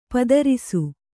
♪ padarisu